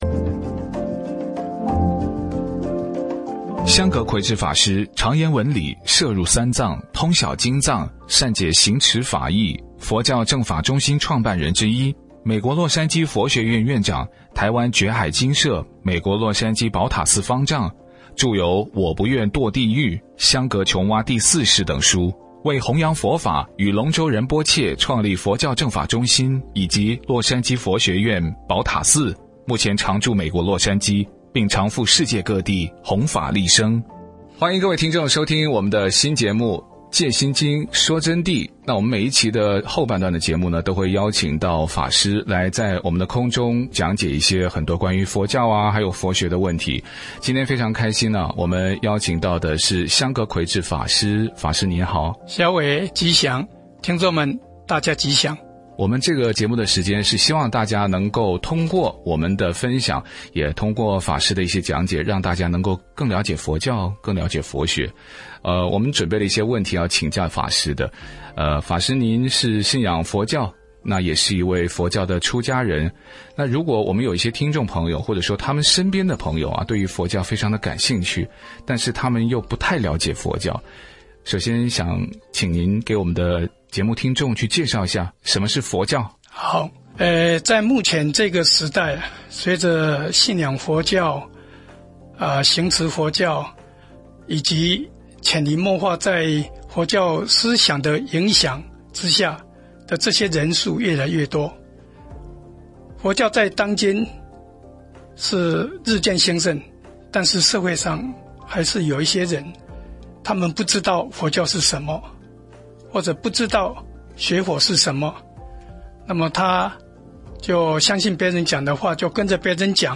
导语：专访节目：「佛弟子访谈」